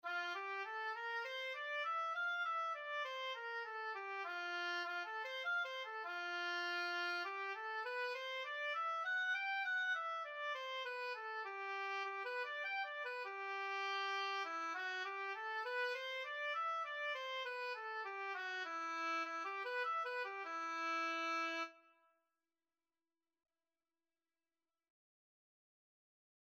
Oboe scales and arpeggios - Grade 1
4/4 (View more 4/4 Music)
E5-G6
F major (Sounding Pitch) (View more F major Music for Oboe )
oboe_scales_grade1_OB.mp3